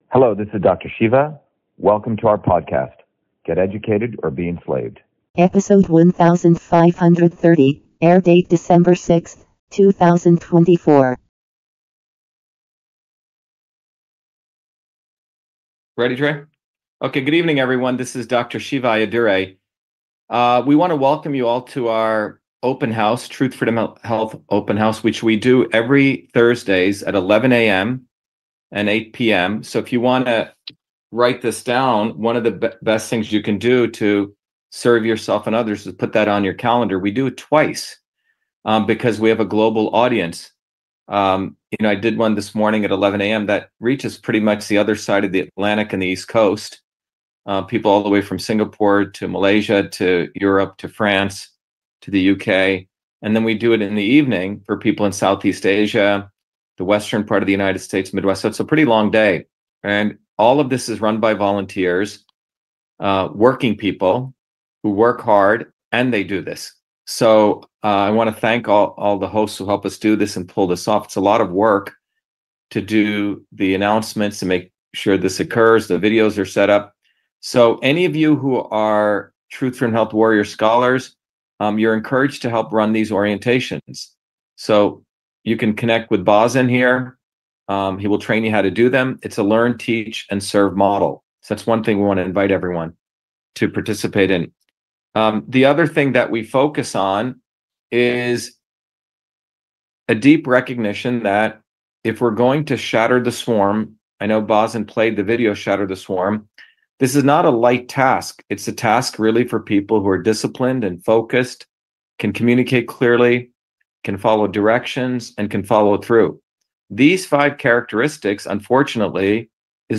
In this interview, Dr.SHIVA Ayyadurai, MIT PhD, Inventor of Email, Scientist, Engineer and Candidate for President, Talks about The BOGUS D.O.G.E. Elon Trump and Vivek The Snake